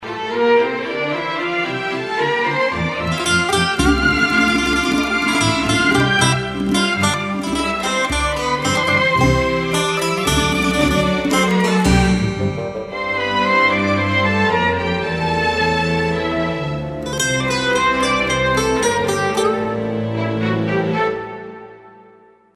زنگ موبایل ملایم و سنتی بی کلام